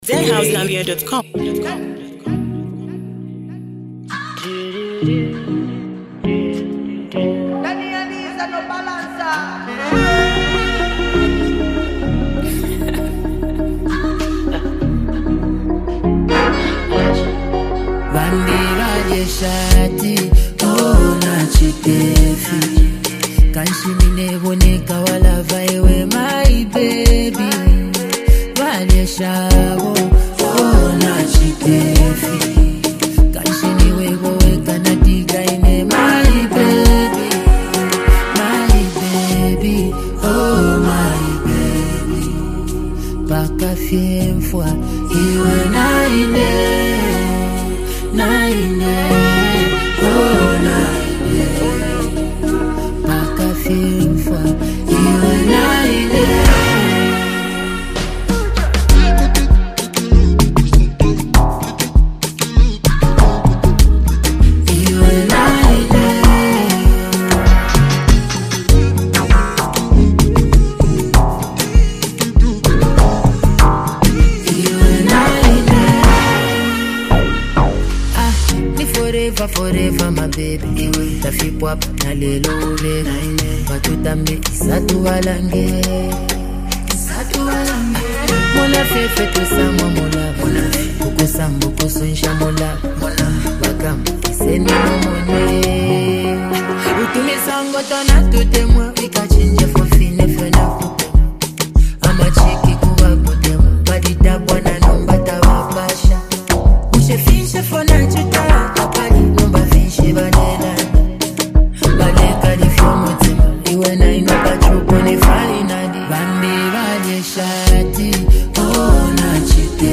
love song
smooth style
emotional vibe